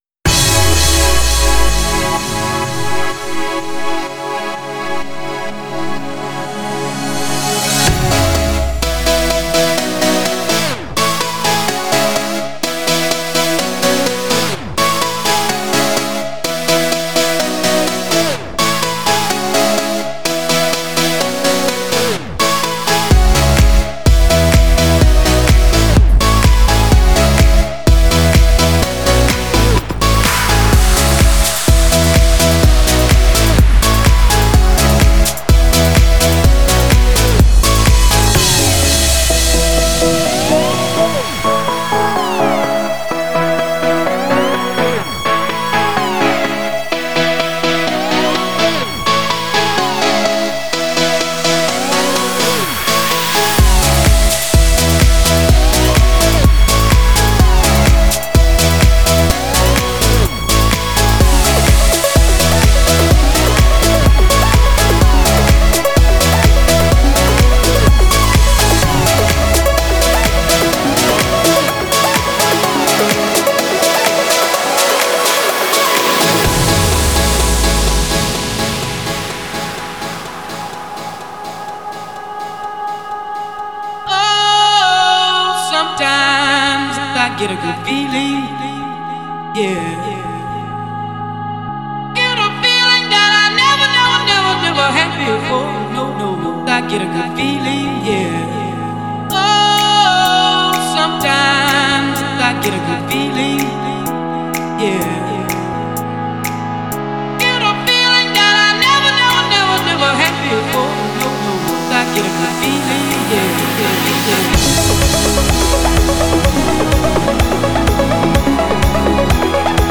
Жанр: Клубняк, размер 7.43 Mb.